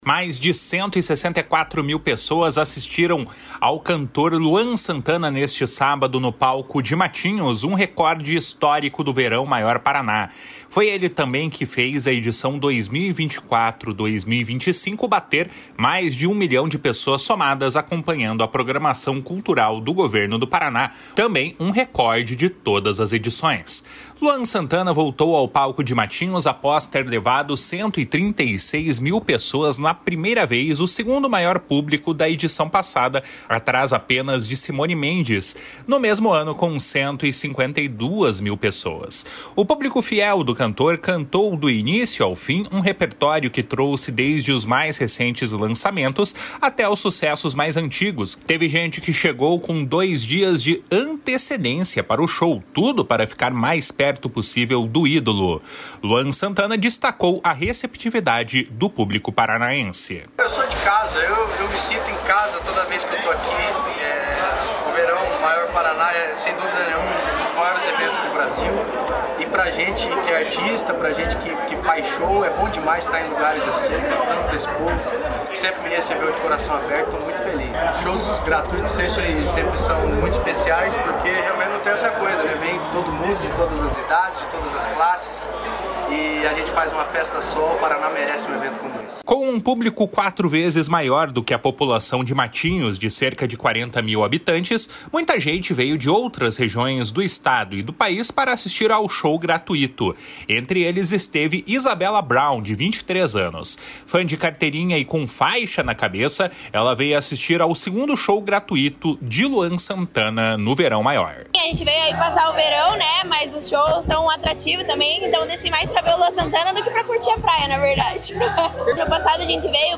// SONORA LUAN SANTANA //
// SONORA LUCIANA SAITO MASSA //